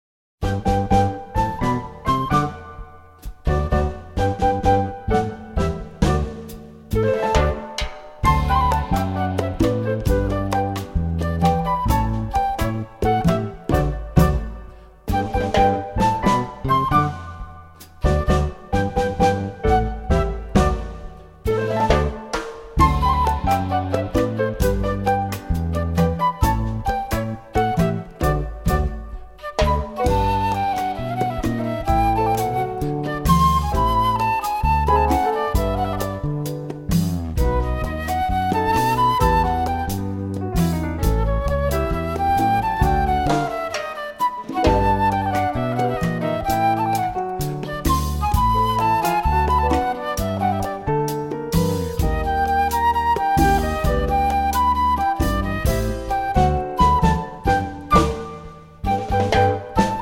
A great balance of latin jazz styles.
tenor saxophone, flute, piccolo
piano, accordion